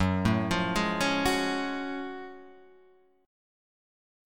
F#mM9 chord {2 0 3 1 2 1} chord